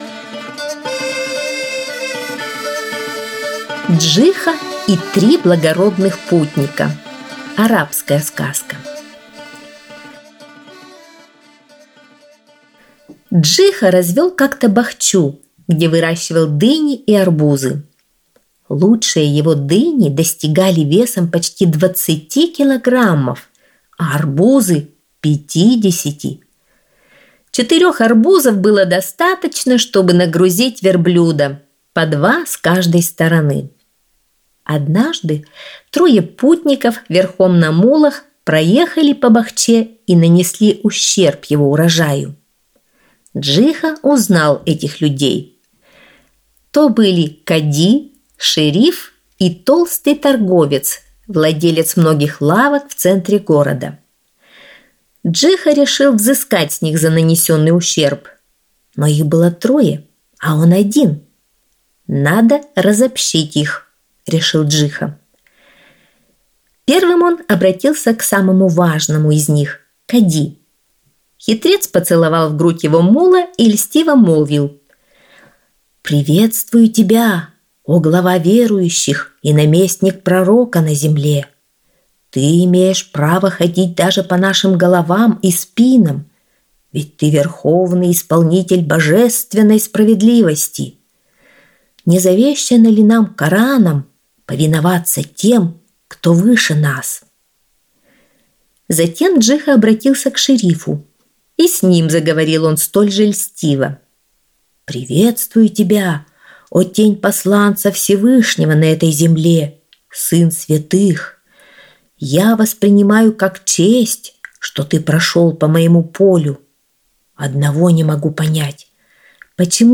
Джиха и три благородных путника – арабская аудиосказка